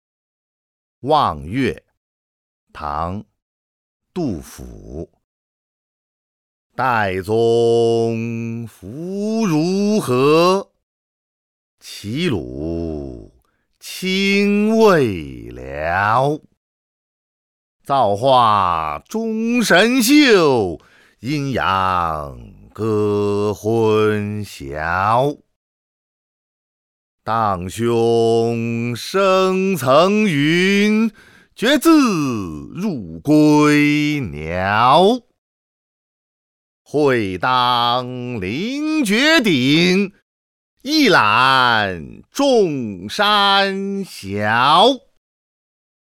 ［唐］杜甫 《望岳》 （读诵）